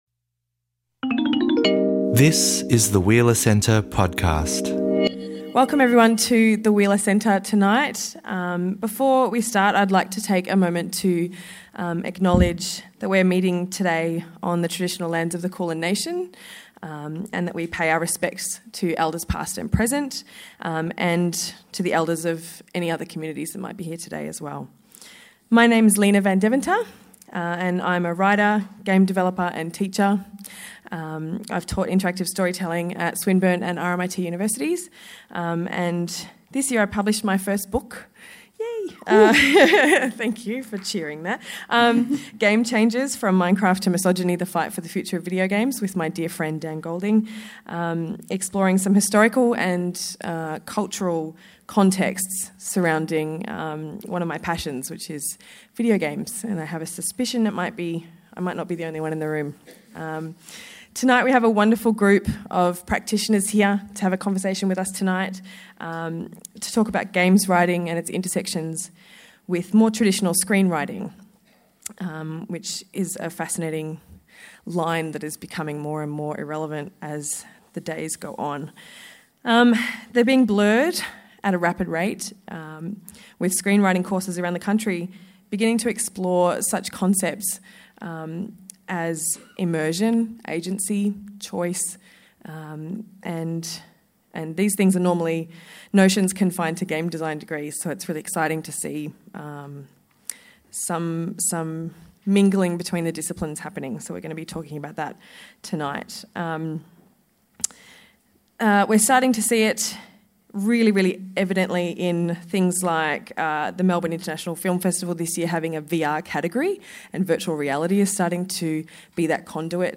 In this talk, we look at the ways writers can seize on the creative and collaborative potential of games.